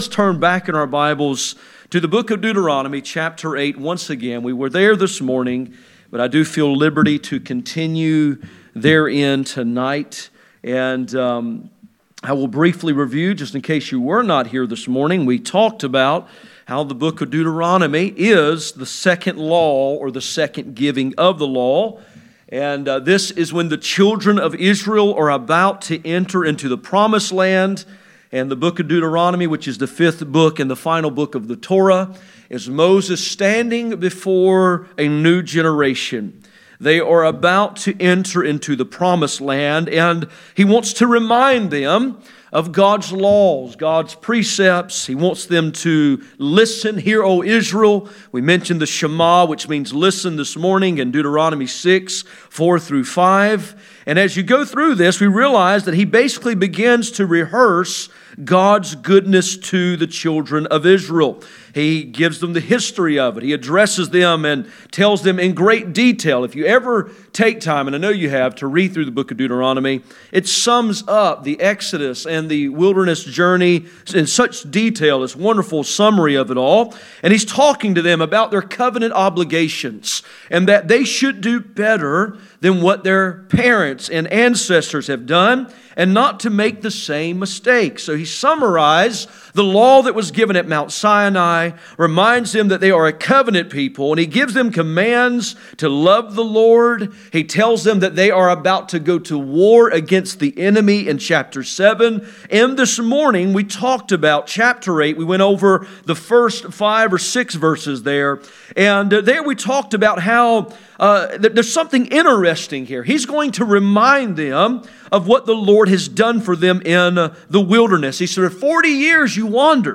Passage: Deuteronomy 8:7-20 Service Type: Sunday Evening